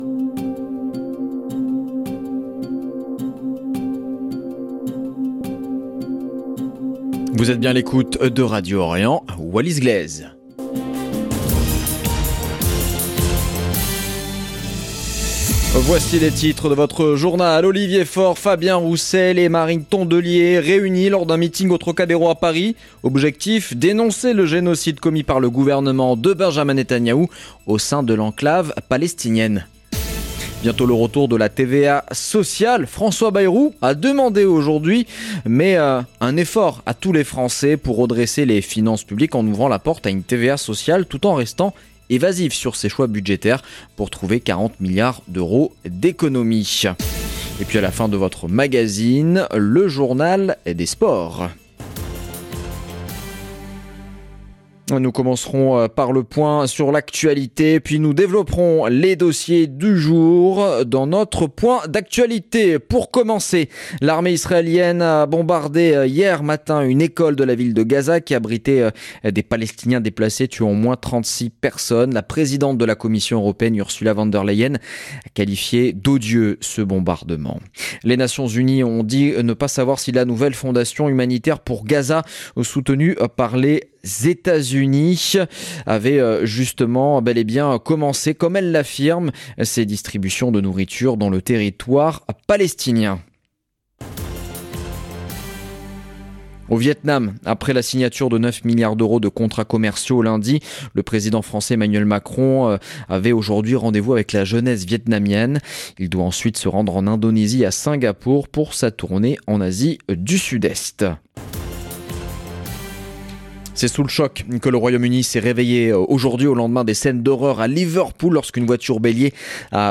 Magazine de l'information du mardi 27 mai 2025